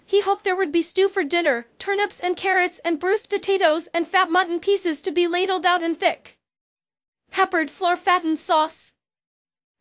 scared.wav